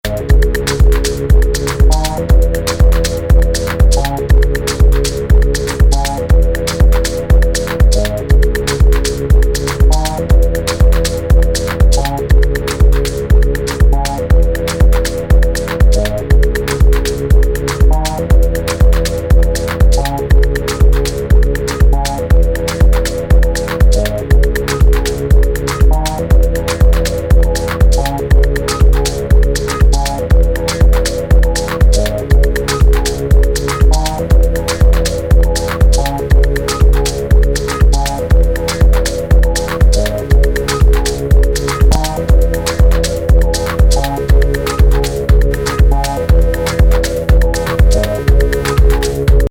ダブやミニマルを基調に、サイケデリックでプログレッシブな
四つ打ちなのに、どこかドローン要素を感じる傑作!